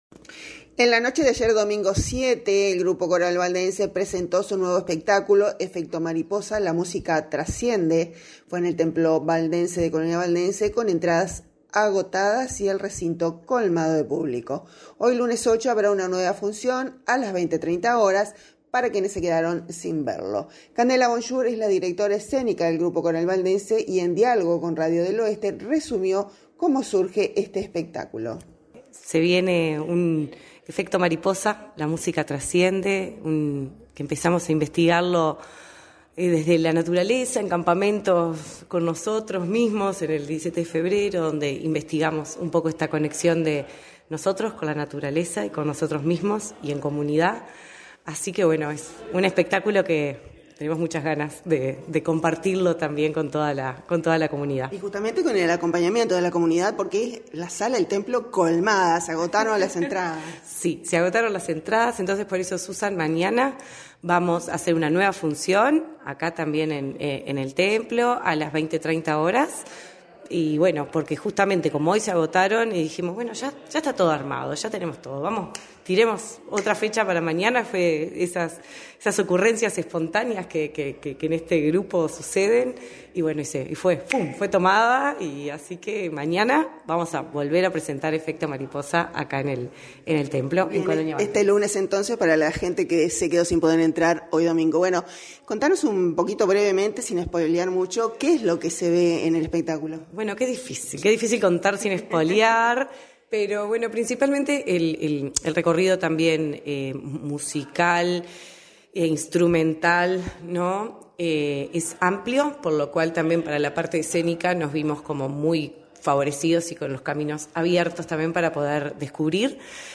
y en diálogo con Radio del Oeste resumió cómo surgió la inspiración del espectáculo y qué podrán encontrar los asistentes esta noche.